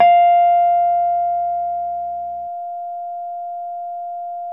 RHODES CL0GR.wav